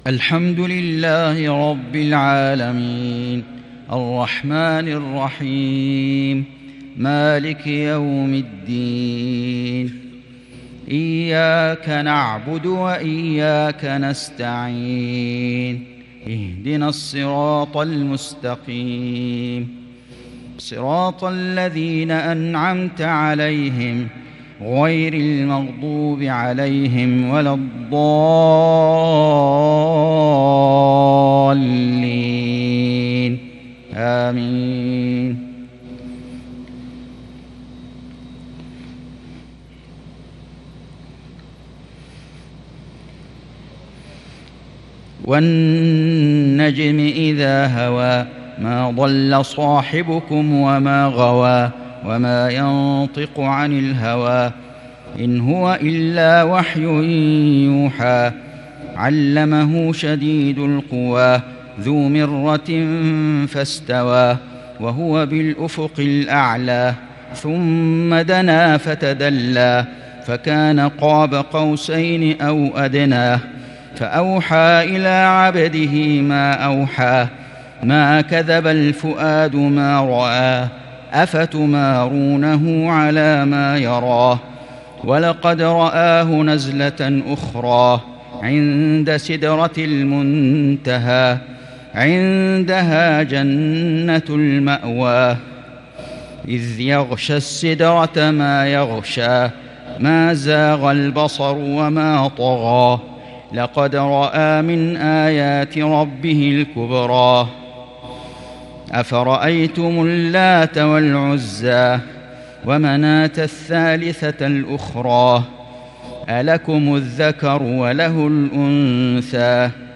عشاء الجمعة 1-2-1442 هـ من سورة النجم | Isha prayer from Surah An-Najm 18/9/2020 > 1442 🕋 > الفروض - تلاوات الحرمين